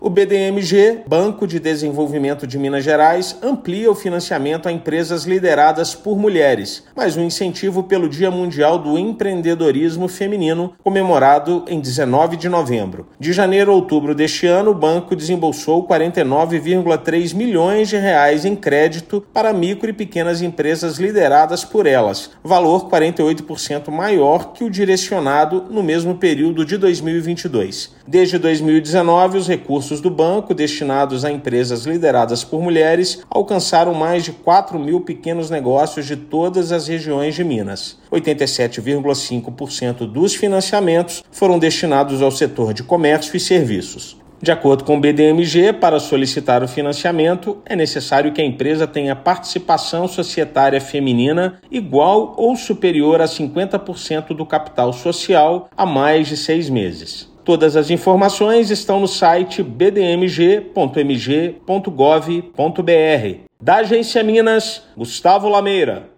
De janeiro a outubro de 2023, banco liberou quase R$ 50 milhões, 48% a mais do que no mesmo período de 2022. Ouça matéria de rádio.